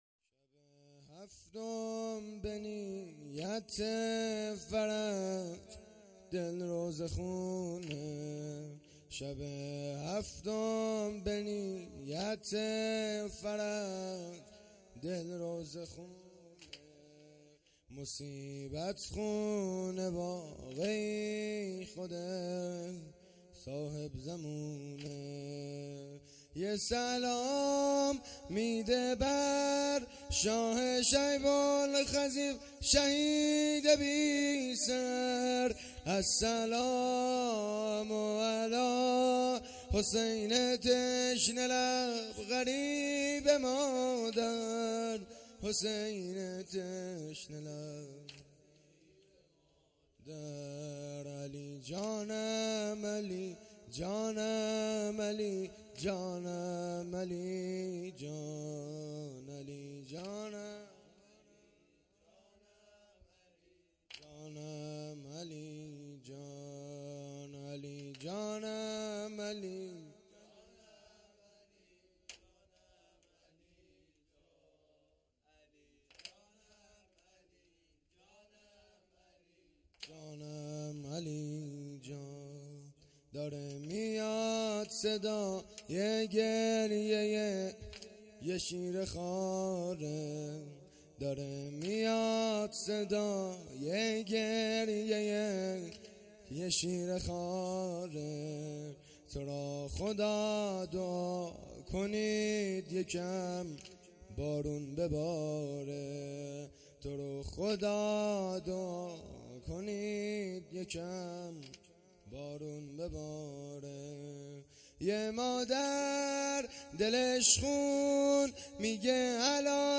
شب هفتم محرم الحرام ۱۴۴۳